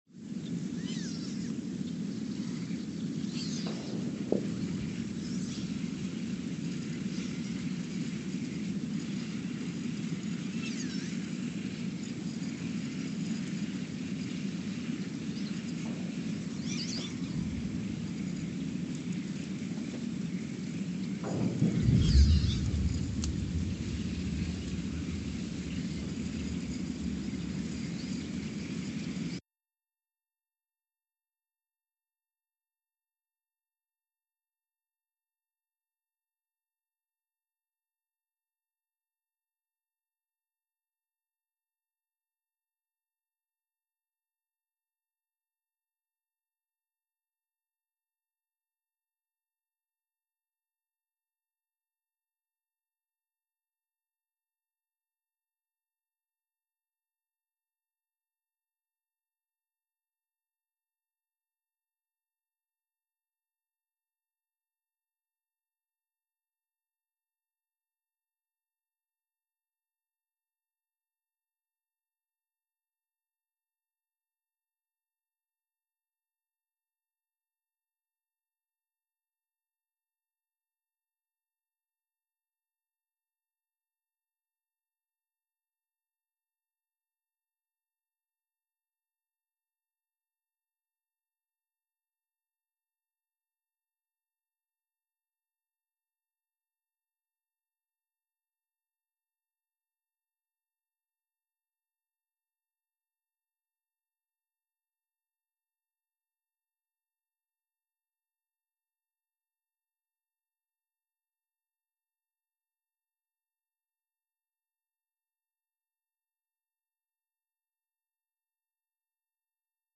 The Earthsound Project is an ongoing audio and conceptual experiment to bring the deep seismic and atmospheric sounds of the planet into conscious awareness.
Sensor : STS-1V/VBB
Speedup : ×900 (transposed up about 10 octaves) Loop duration (real) : 168 hours